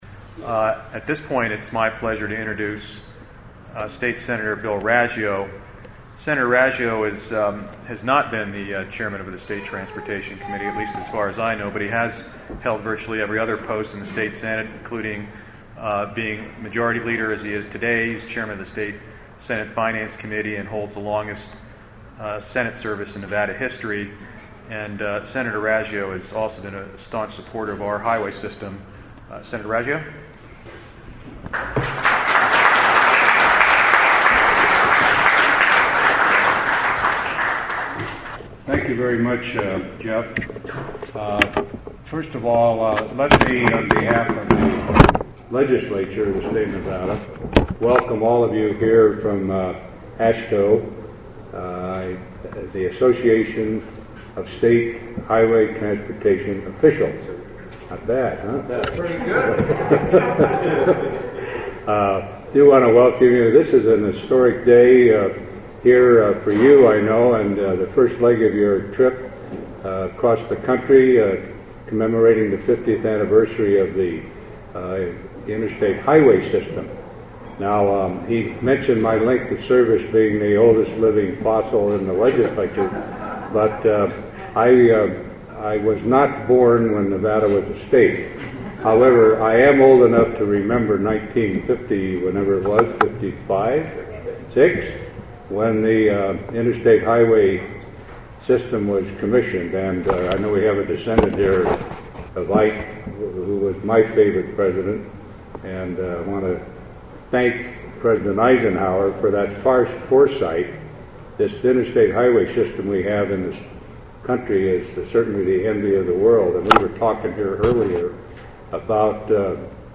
Nevada Sen. Bill Raggio Remarks (4 min MP3)